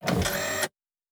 pgs/Assets/Audio/Sci-Fi Sounds/Mechanical/Servo Small 3_1.wav at master
Servo Small 3_1.wav